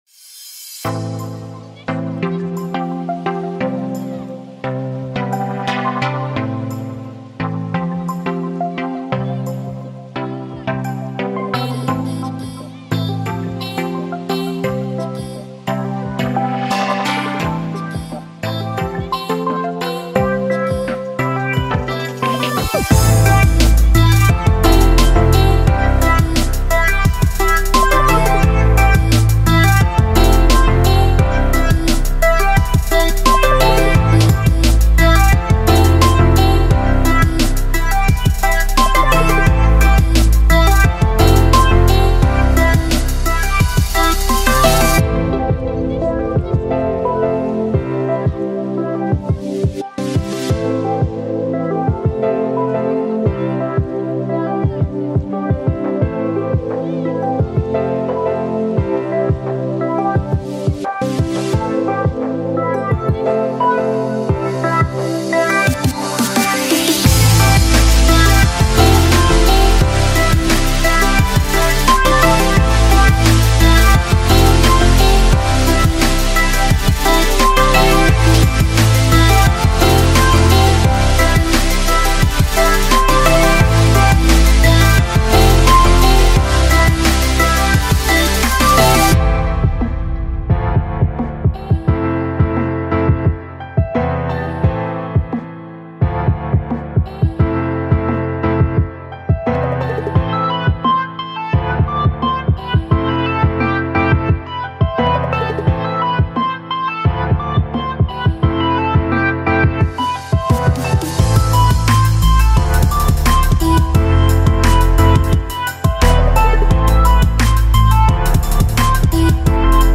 timer_alarm.mp3